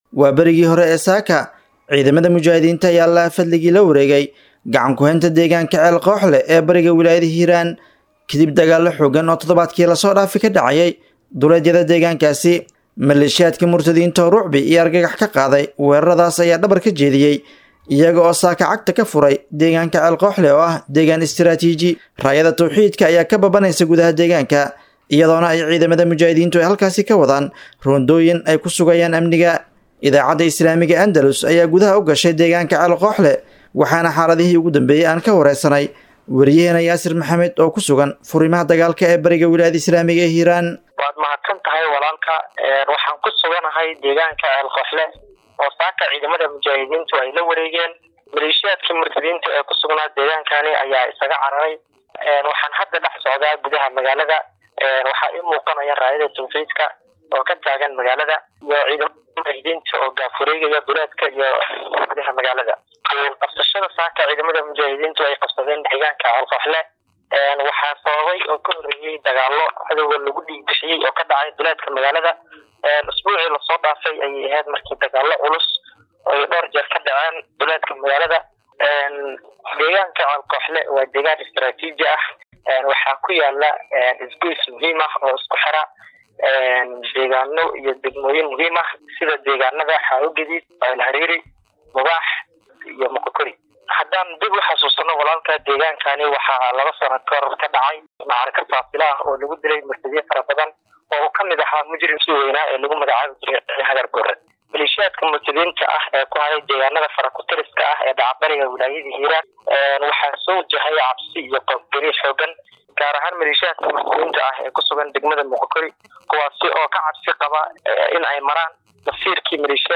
Halkan Ka Dhagayso Warbixinta Oo Dhameystiran.